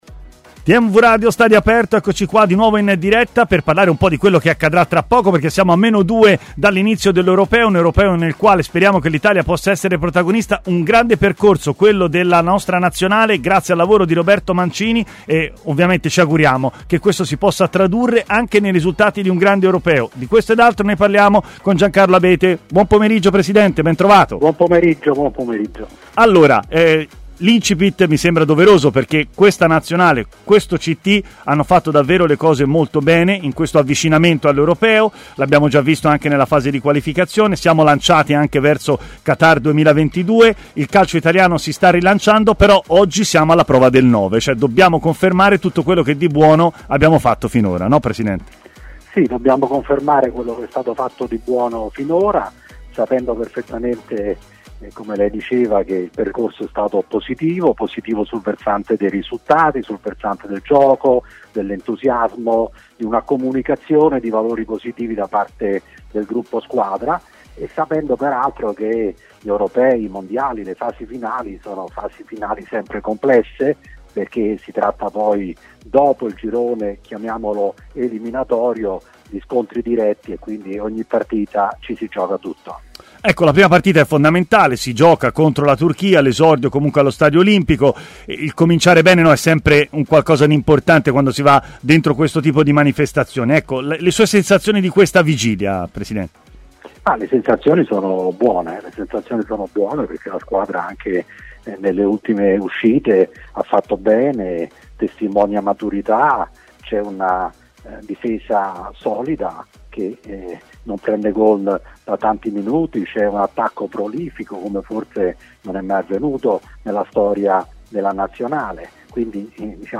nel corso della trasmissione Stadio Aperto su TMW Radio